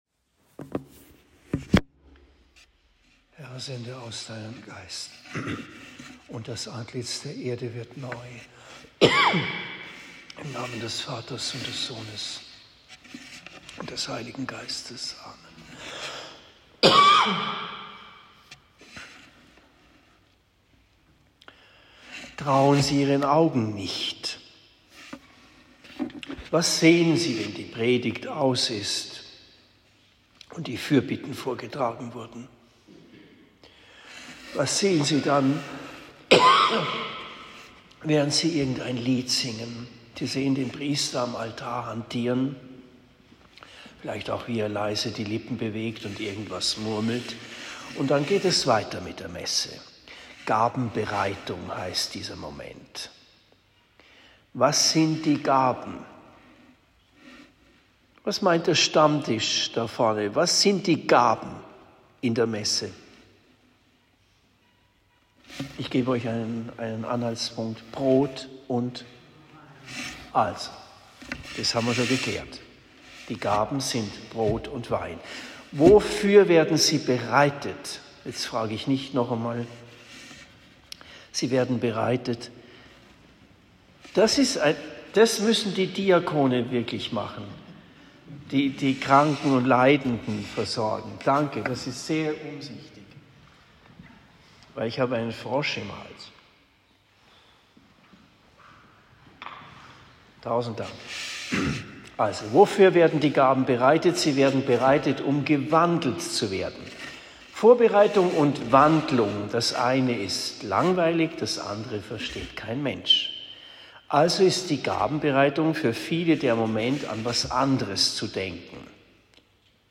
Predigt in Bischbrunn am 06. März 2023